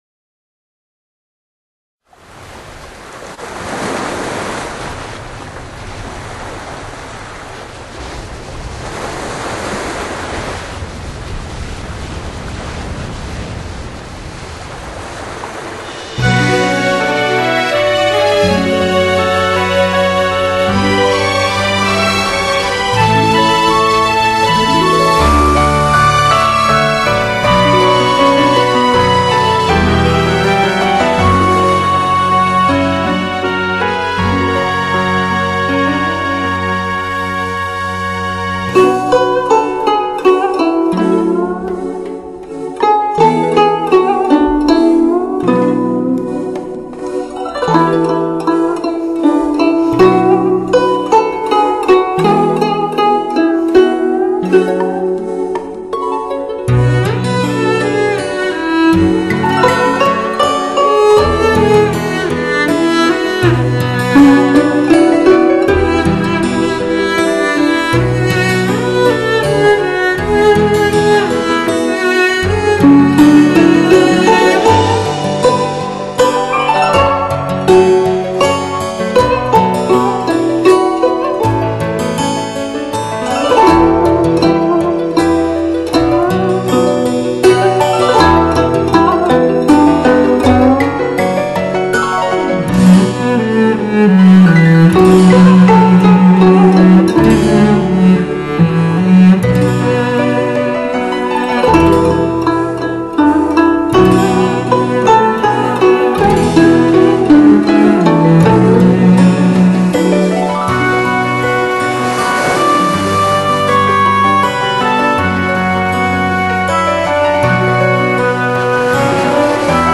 纯粹的声音，来自东方的神秘旋律，触动着你的